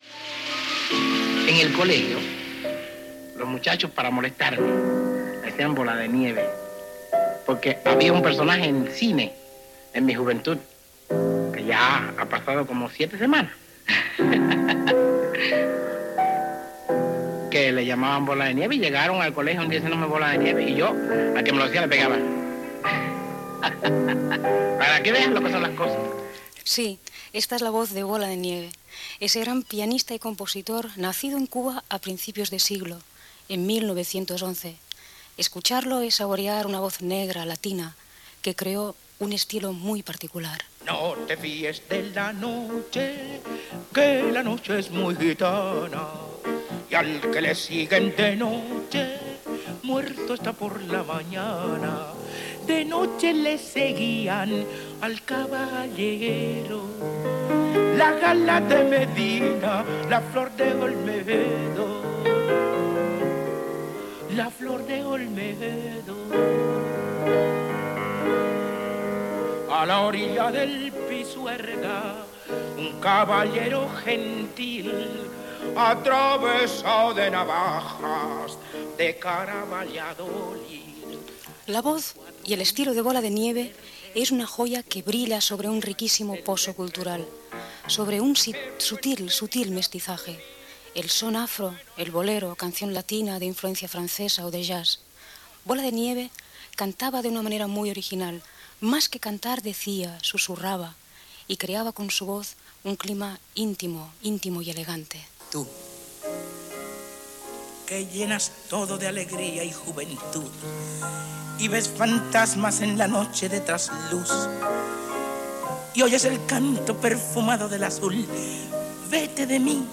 Col·laboració de Marina Rossell. Paraules del pianista Bola de Nieve, temes musicals seus i comentaris, tancament de la secció per part dels presentadors, promoció d'Onda Cero
Entreteniment